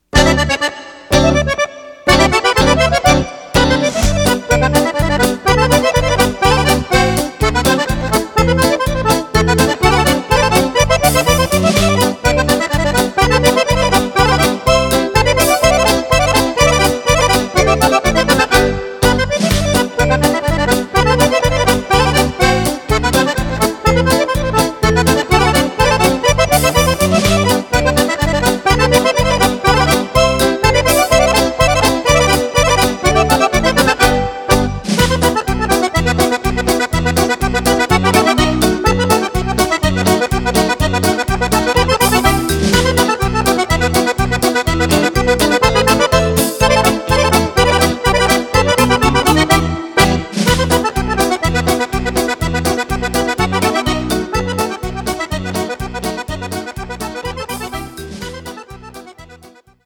Polca
base con seconda fisa e spartito seconda fisa